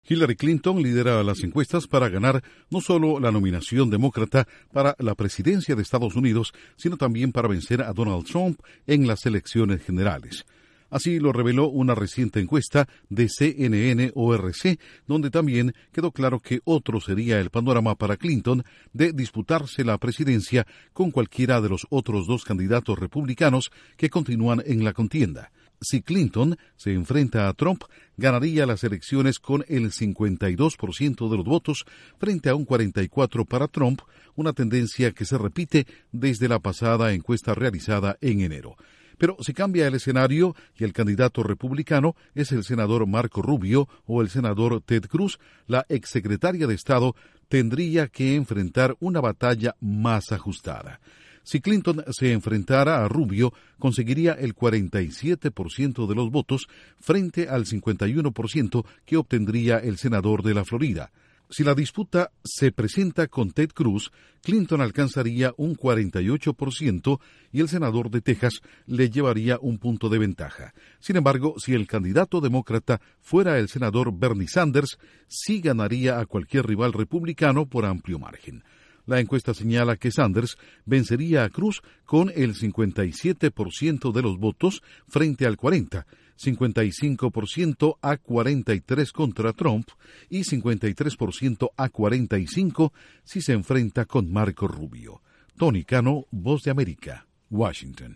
Una nueva encuesta pronostica que sea cual fuese el candidato demócrata para la presidencia de Estados Unidos, ganaría las elecciones de noviembre. Informa desde la Voz de América